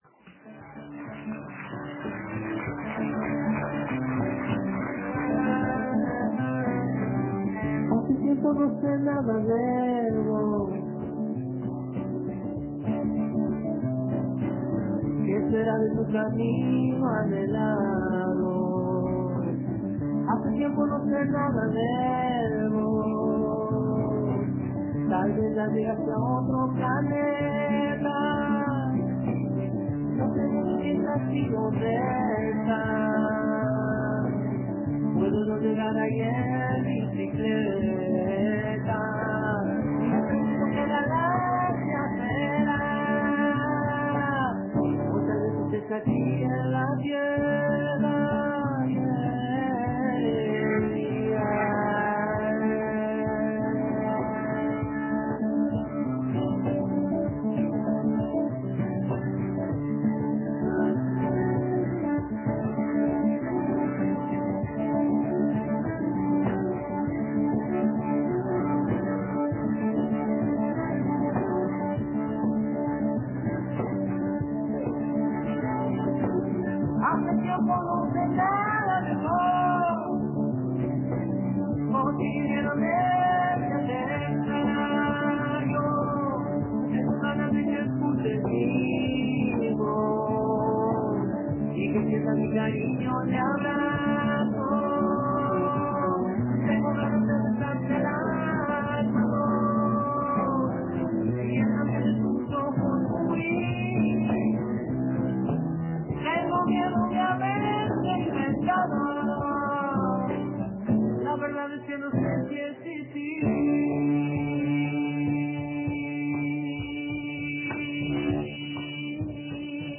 vino a hacer "canciones transparentes" para la fonoplatea de El Espectador. Además, compartió con los cocafeteros sus comienzos en la música y anécdotas de su vida.